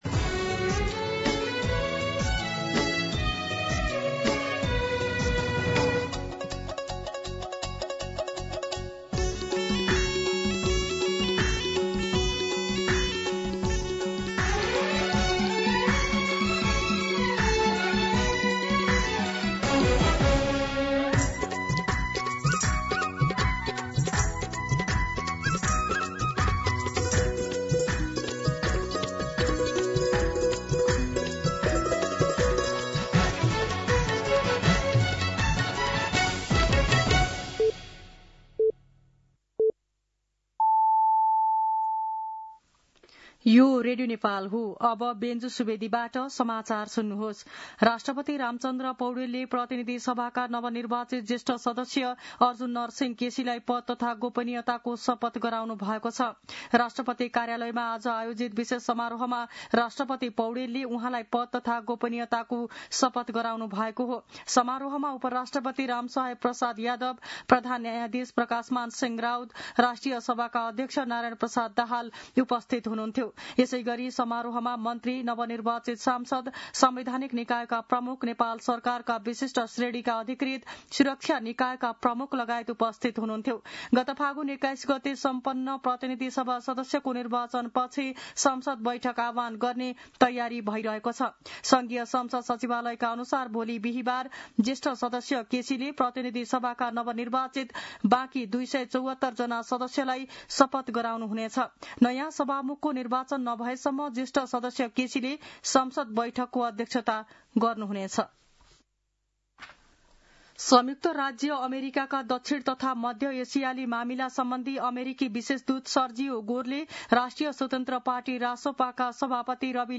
दिउँसो १ बजेको नेपाली समाचार : ११ चैत , २०८२
1pm-Nepali-News.mp3